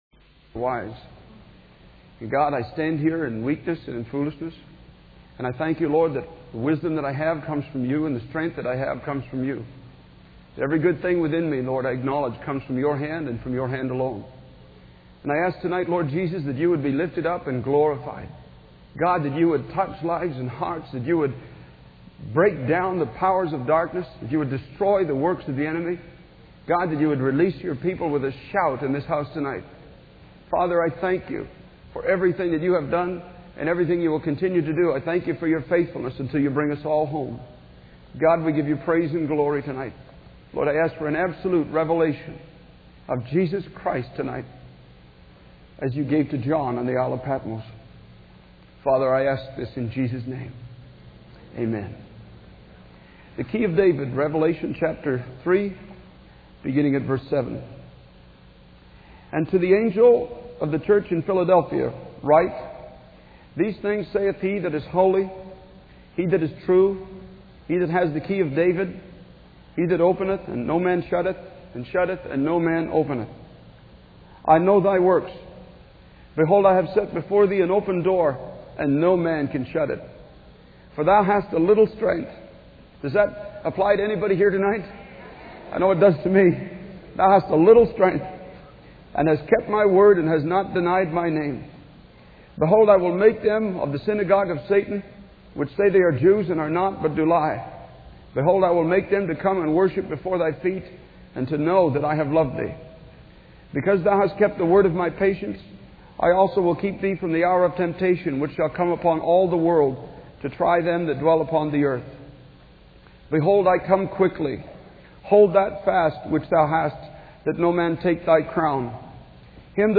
In this sermon, the preacher emphasizes the importance of relying on God's strength rather than our own. He uses the example of Jesus carrying his cross to illustrate this point. The preacher encourages those who feel weak and defeated to recognize that their strength comes from God and that they have not failed Him.